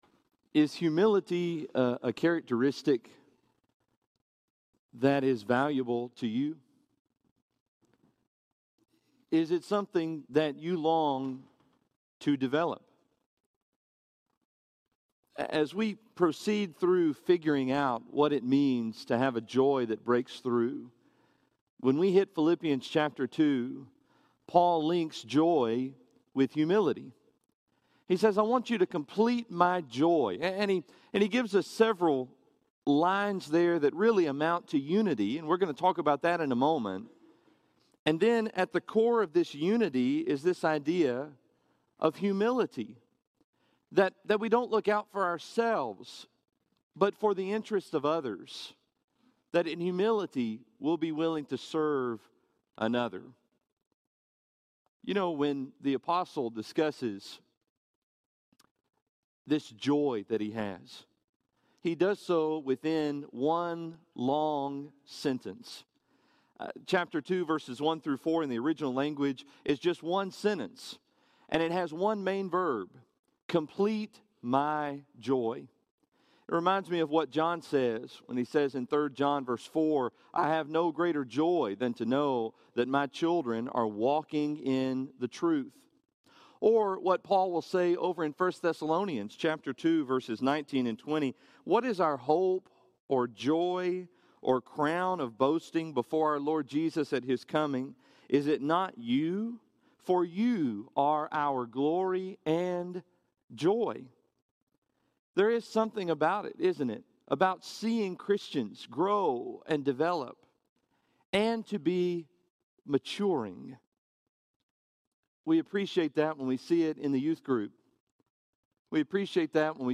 Breakthrough Humility – Henderson, TN Church of Christ